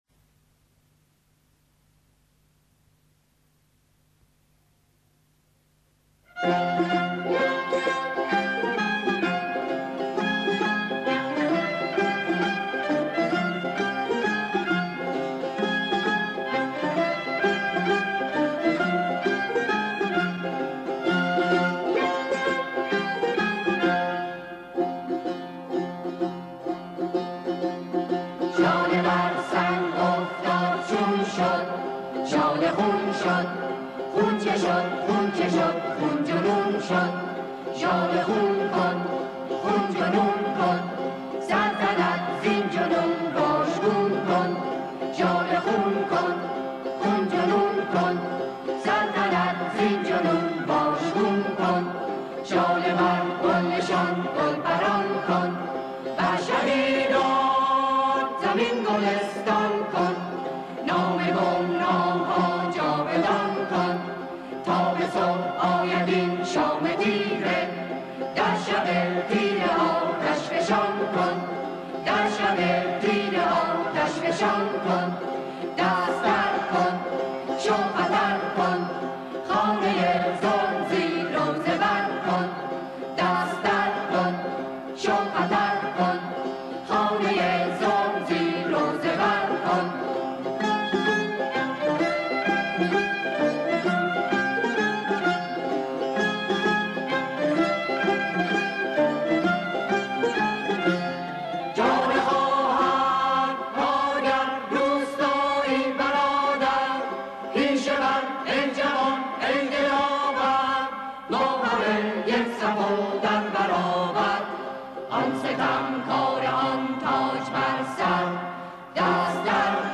ابتدا بدون تكخوان و فقط با همخواني گروه